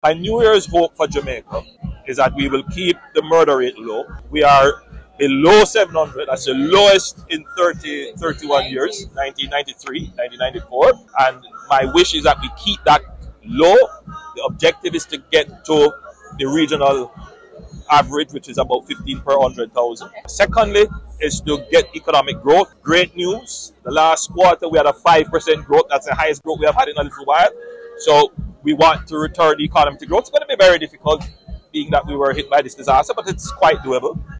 He made the statement at the new year's ceremony in Black River St Elizabeth.